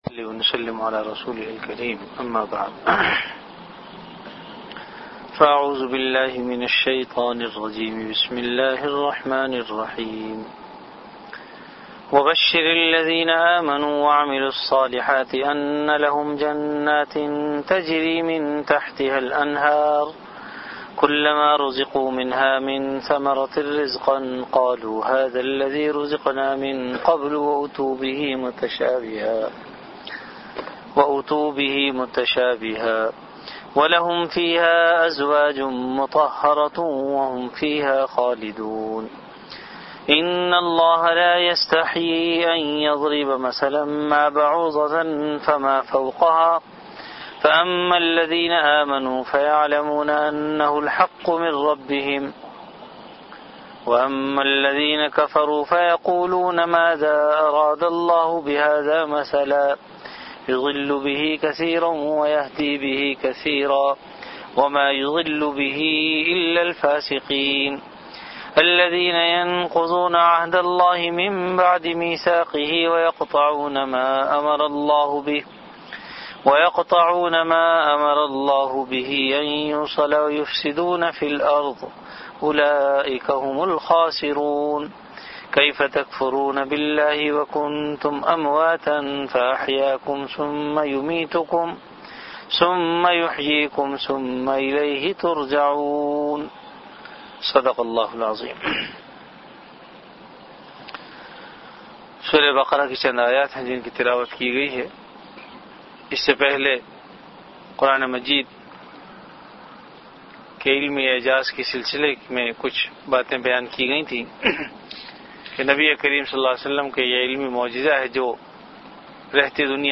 Delivered at Jamia Masjid Bait-ul-Mukkaram, Karachi.
Dars-e-quran · Jamia Masjid Bait-ul-Mukkaram, Karachi